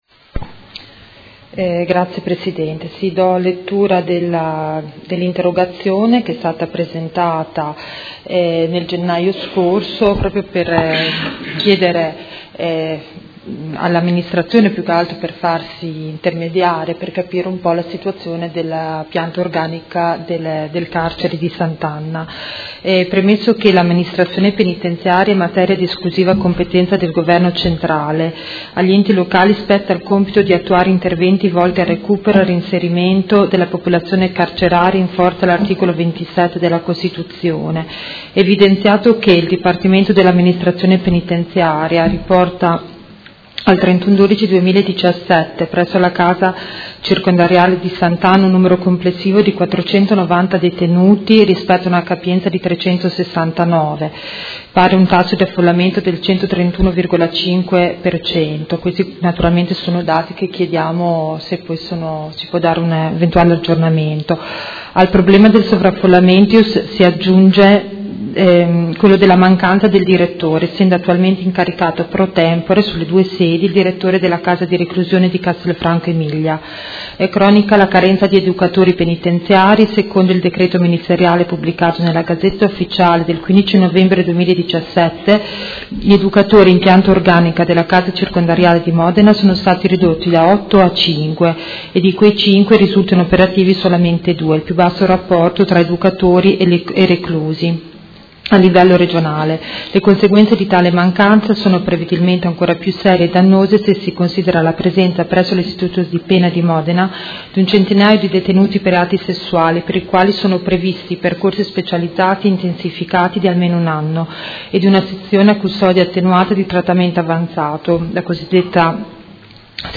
Seduta del 26/03/2018 Interrogazione delle Consigliere Pacchioni e Baracchi (PD) avente per oggetto: Situazione della popolazione carceraria modenese